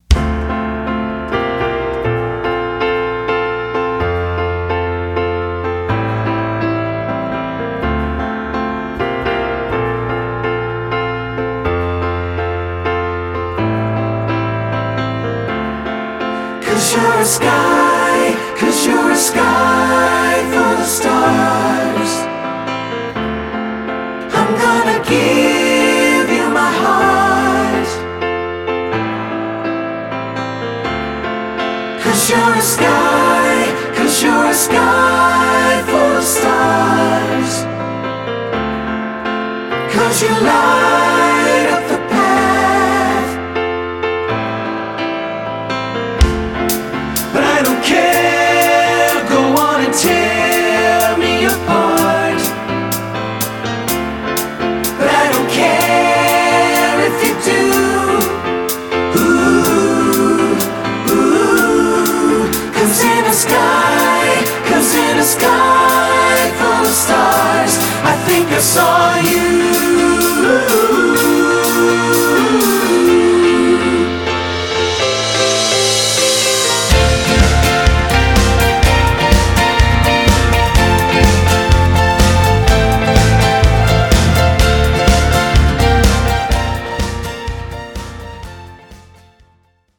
Choral Early 2000's Pop
SAB